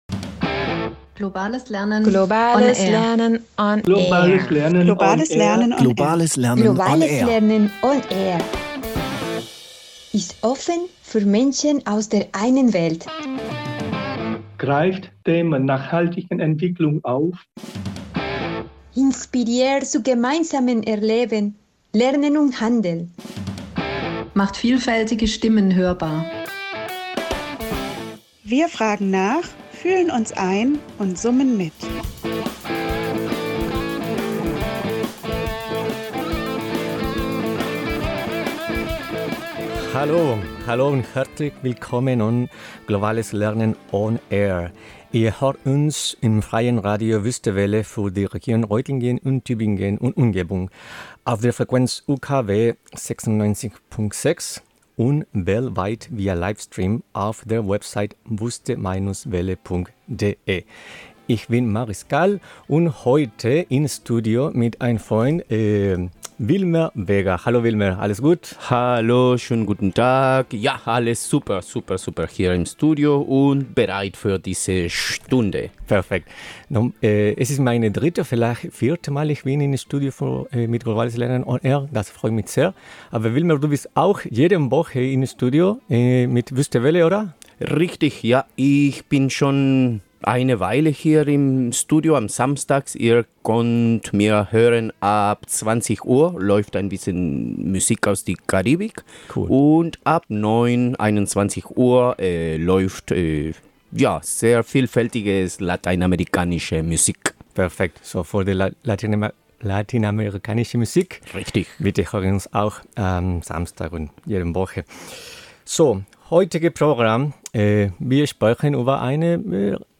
diskutieren und teilen ihre eigenen Erfahrungen und Erinnerungen an Palästina